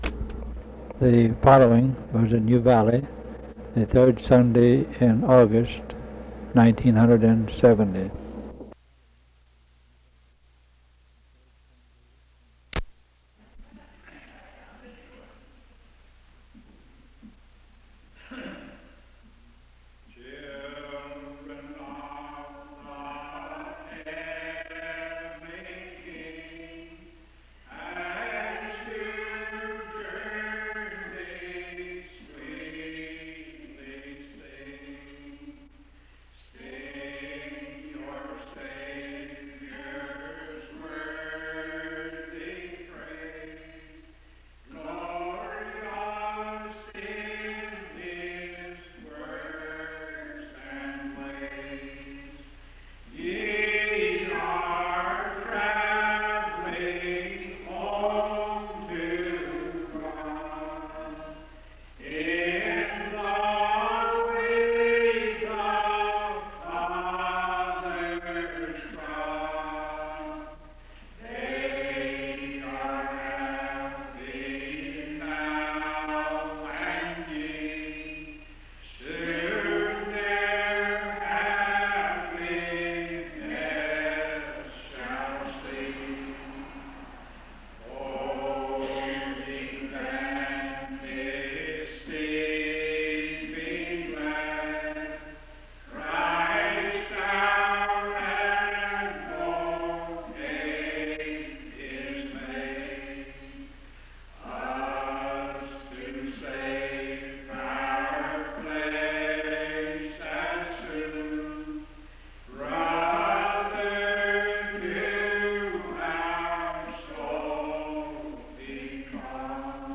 Ephesians 1:1-6, (& singing at Mt. Zion?)